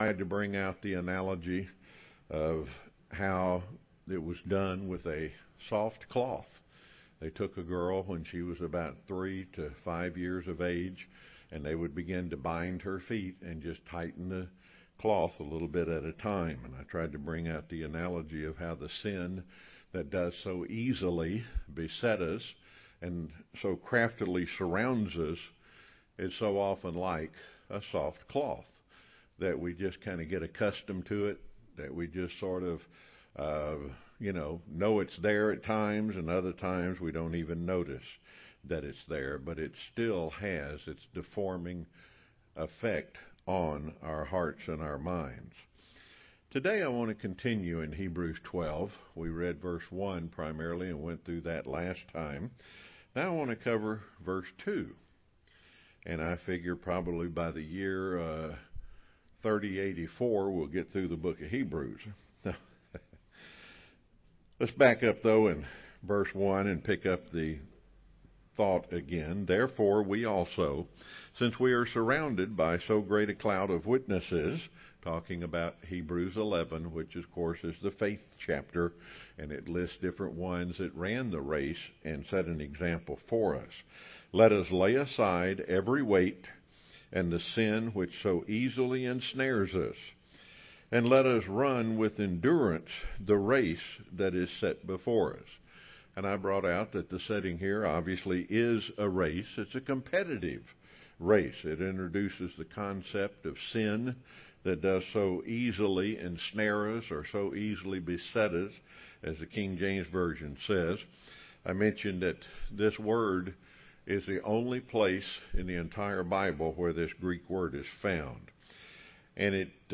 Given in Rome, GA
UCG Sermon Studying the bible?